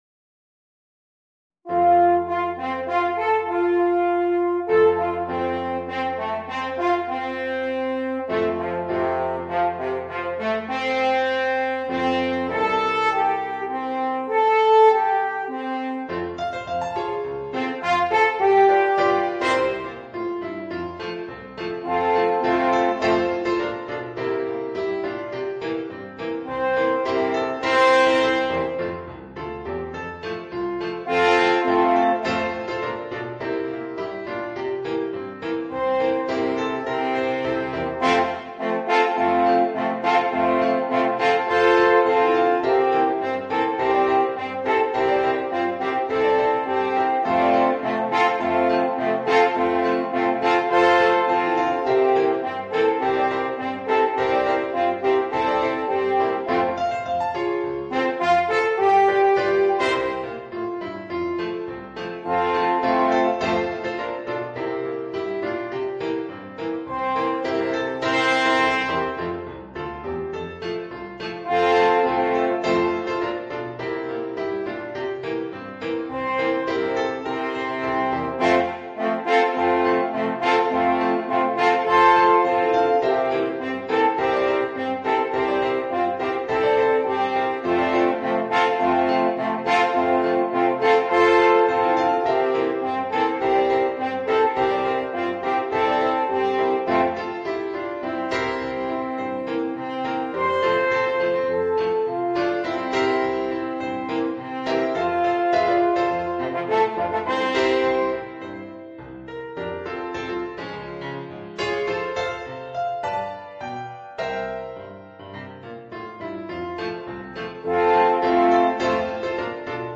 Voicing: 3 Alphorns and Piano